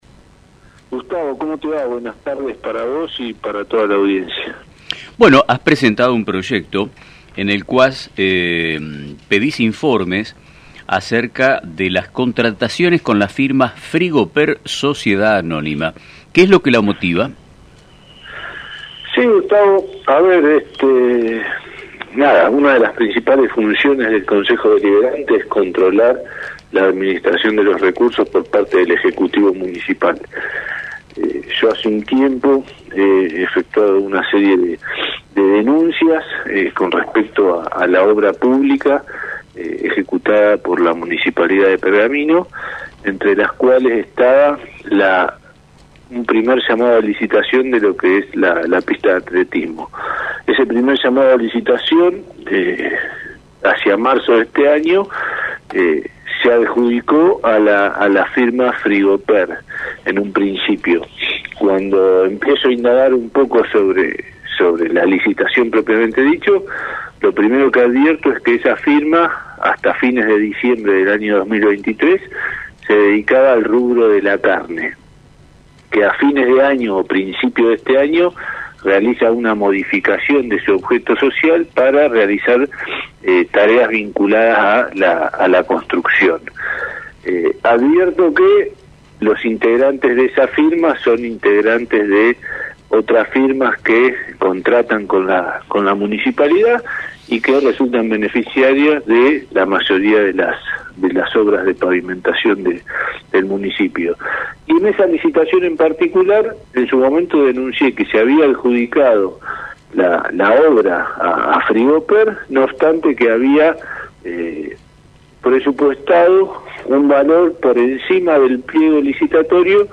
En una reciente entrevista en el programa radial *Nuestro Tiempo*, el concejal Ramiro Llan de Rosos presentó un proyecto solicitando informes detallados acerca de las contrataciones realizadas por la Municipalidad de Pergamino con la firma Frigo Per S.A. El concejal expresó su preocupación sobre la transparencia de estas contrataciones, destacando que la empresa ha recibido adjudicaciones por un valor aproximado de 2.300 millones de pesos, lo que equivale al 10% del presupuesto municipal.